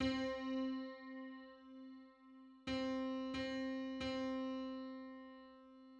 Just: 145 : 144 = 11.98 cents.
Licensing [ edit ] Public domain Public domain false false This media depicts a musical interval outside of a specific musical context.